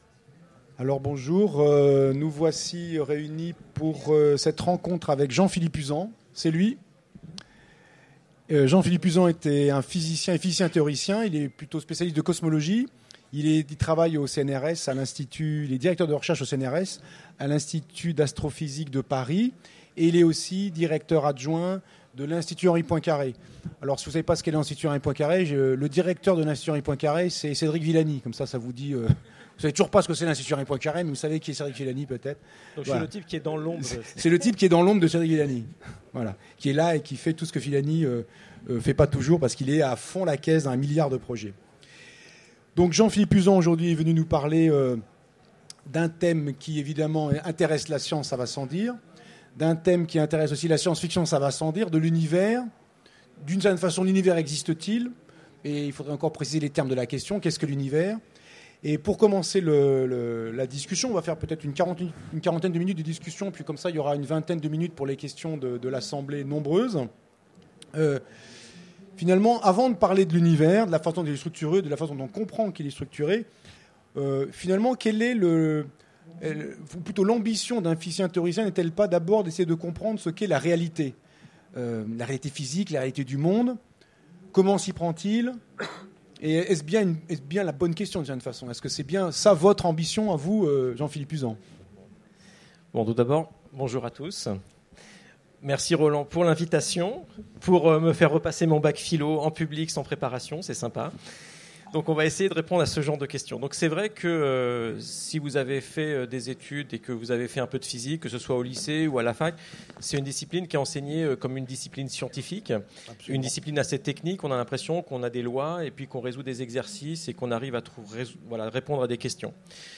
- le 31/10/2017 Partager Commenter Utopiales 2015 : Rencontre avec Jean-Philippe Uzan Télécharger le MP3 à lire aussi Jean-Philippe Uzan Genres / Mots-clés Rencontre avec un auteur Conférence Partager cet article